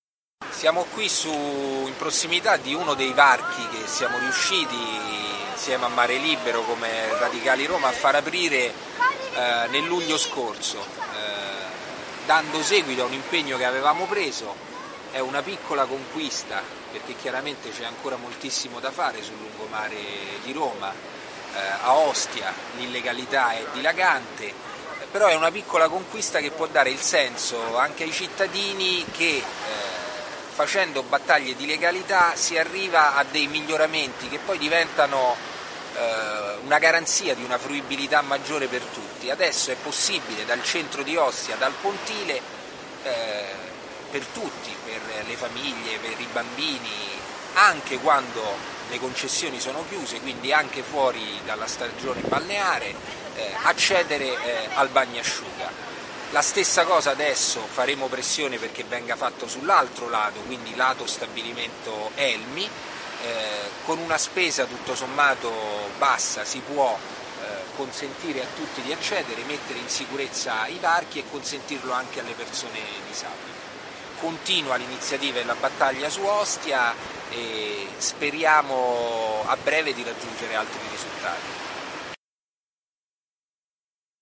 Ostia, lido di Roma, 3 marzo 2015.